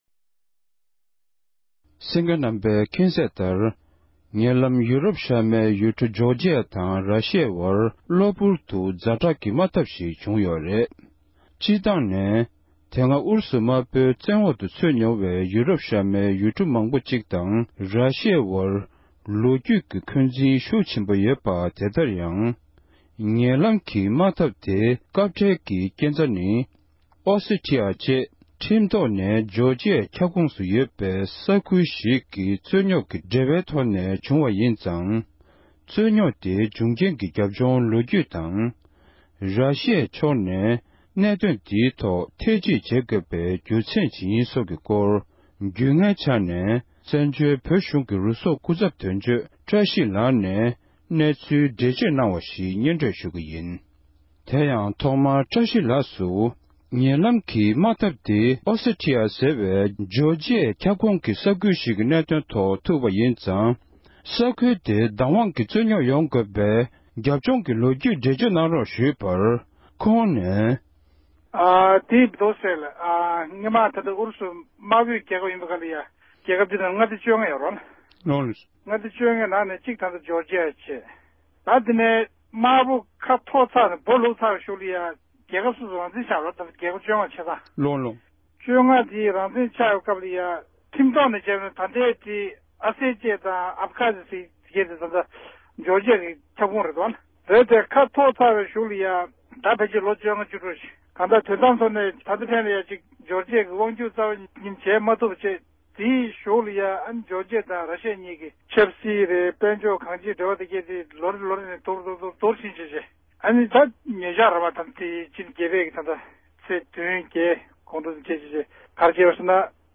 གནས་འདྲི་ཞུས་པར་གསན་རོག་གནང༌༎